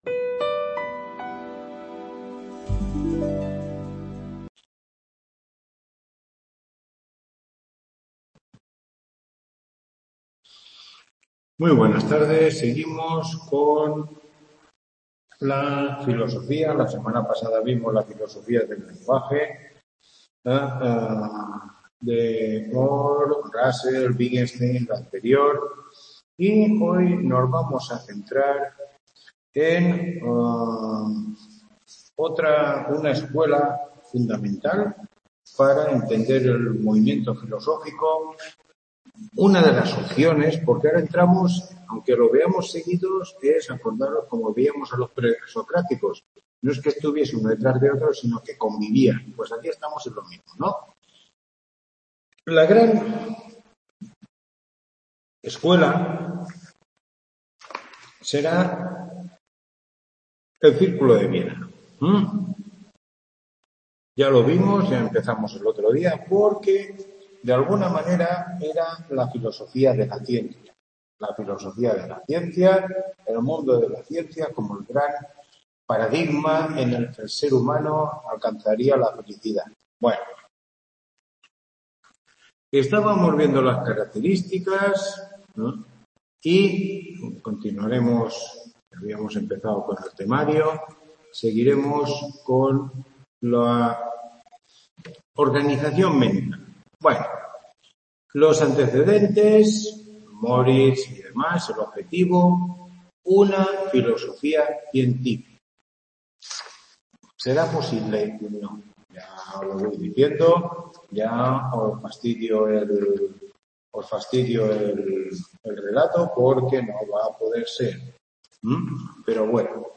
Tutoría 8